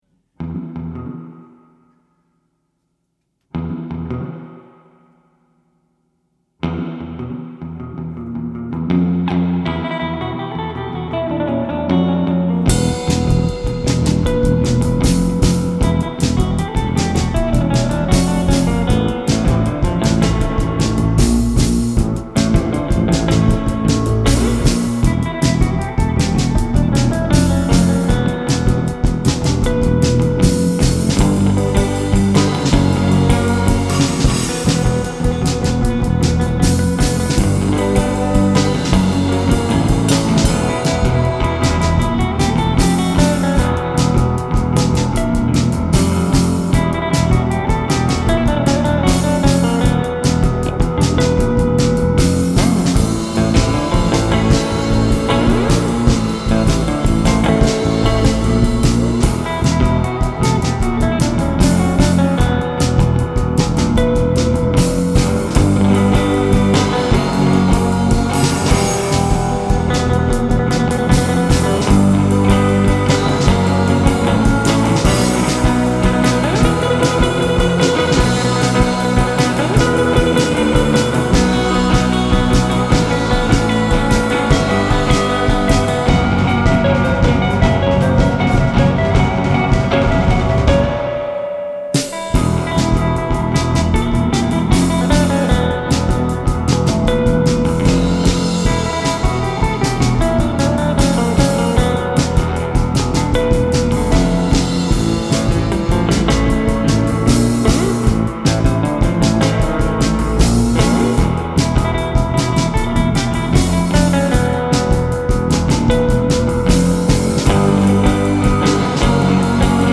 guitar.
drums.
bass.